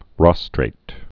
(rŏstrāt, -trĭt, rôstrāt, -strĭt)